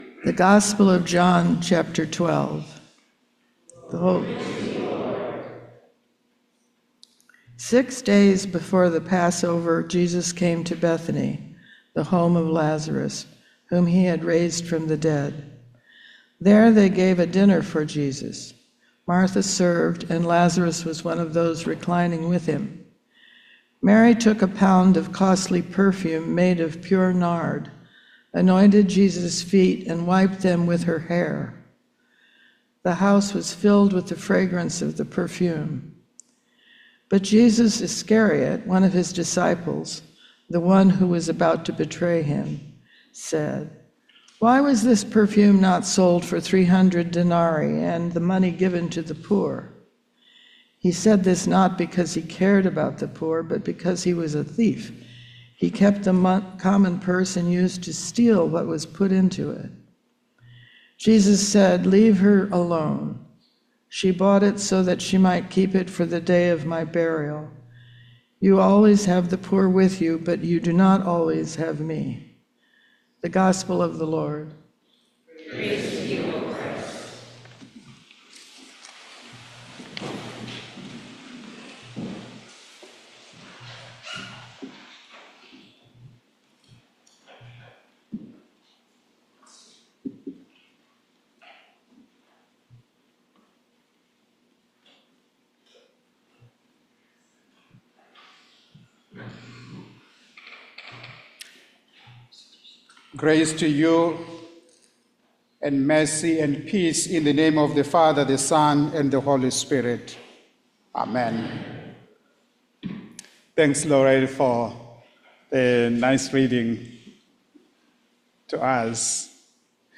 This recording begins with the lector’s reading from the Gospel of John.